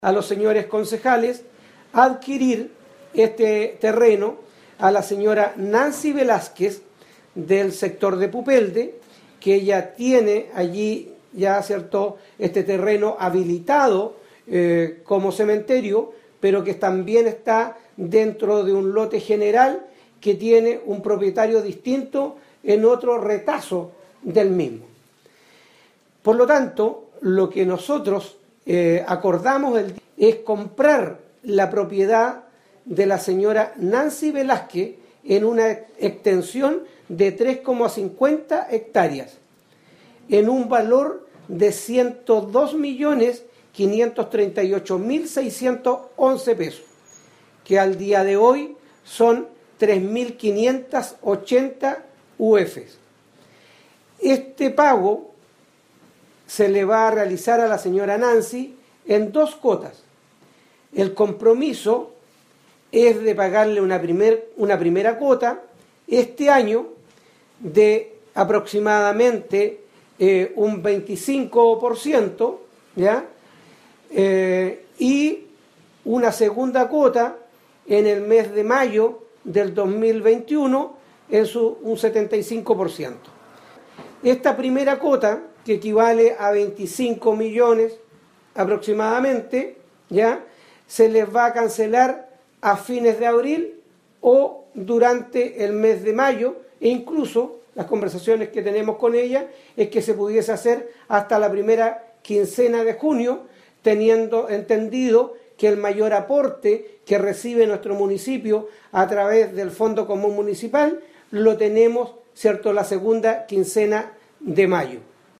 Sobre la compra del terreno para cementerio de la comuna de Ancud, el alcalde Carlos Gómez, explicó la modalidad en que se adquirió el terreno del sector de Pupelde para tales fines.